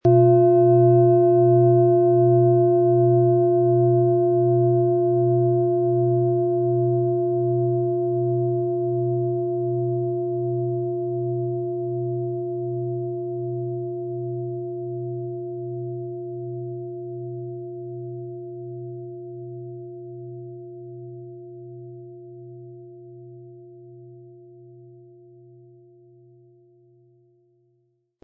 Es ist eine von Hand geschmiedete Klangschale, die in alter Tradition in Asien von Hand gefertigt wurde.
• Mittlerer Ton: Platonisches Jahr
Um den Original-Klang genau dieser Schale zu hören, lassen Sie bitte den hinterlegten Sound abspielen.
PlanetentöneDelphin & Platonisches Jahr
MaterialBronze